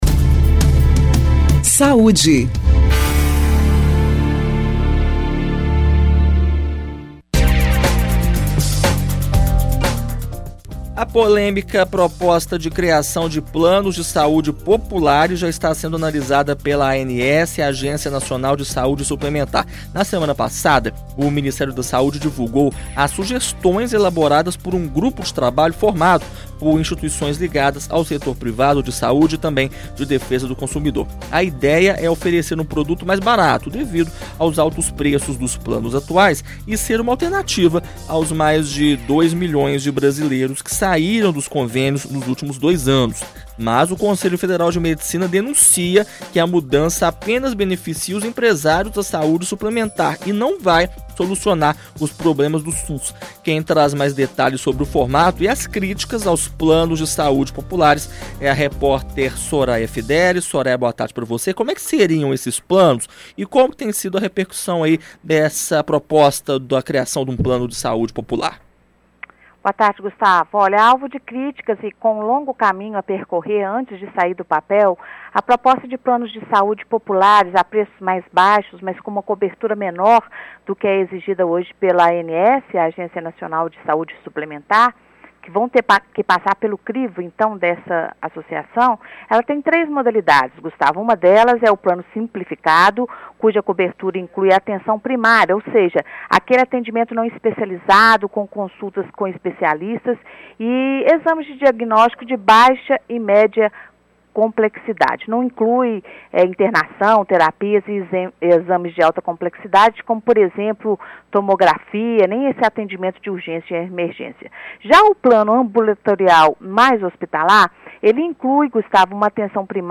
Reportagens da produção